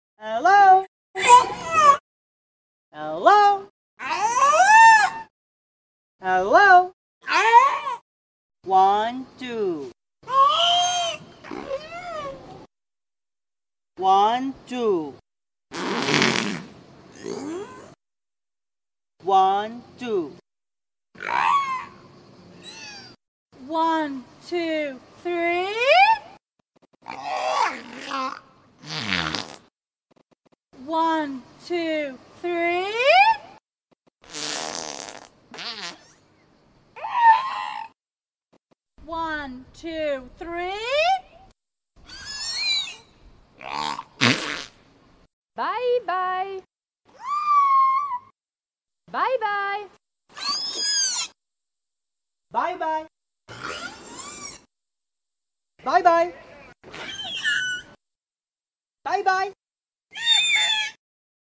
A nőstény delfin trénerét utánozva tanult meg „kimondani” néhány angol szót egy franciaországi tengeri vidámparkban.
Felvételeken hallani, amint rikoltó vagy élesen sípoló hangokkal utánozza többek között az angol számneveket.
Wikie úgy adta ki az emberi beszédre emlékeztető hangokat, hogy közben az orrnyílása kint volt a vízből.